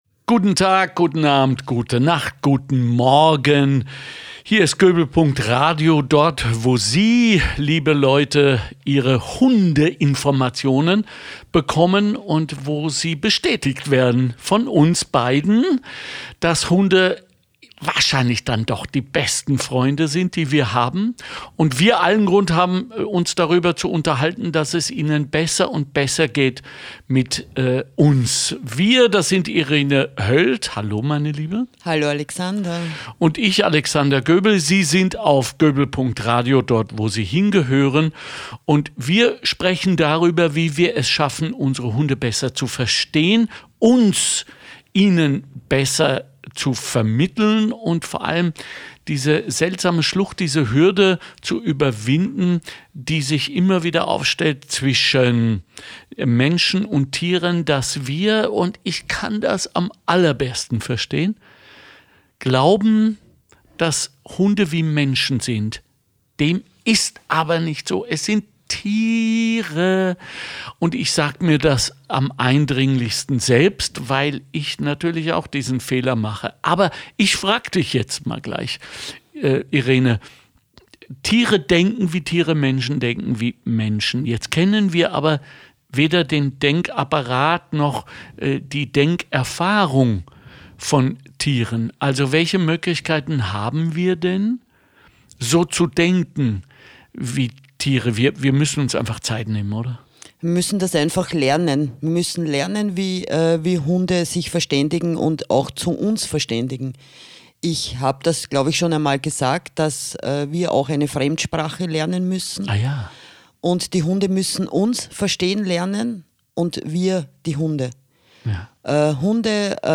Wir sprechen darüber ob Hunde sich als Weihnachtsgeschenk eignen, über Christbäume und Lulu, über Verkleidungen und Würde. Ein schönes, atmosphärisches Gespräch, stellvertretend für alle unsere Podcasts.